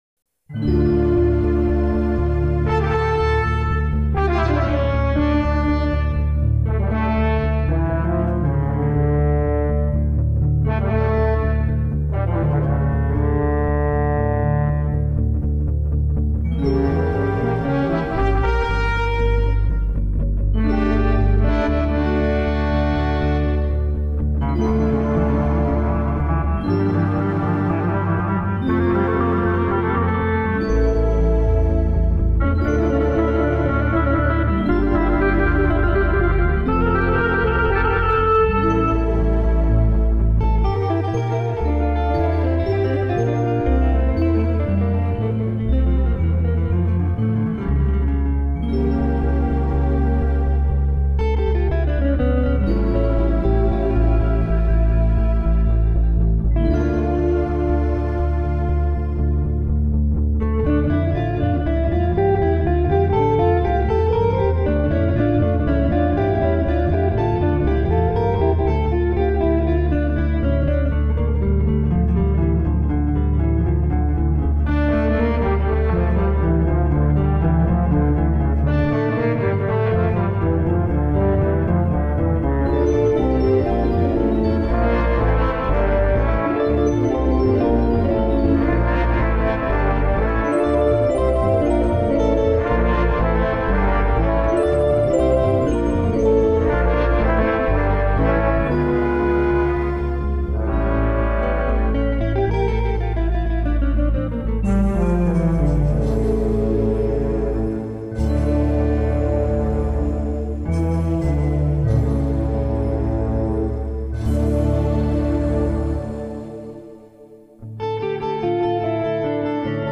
Toccata and Fugue in D minor, BWV565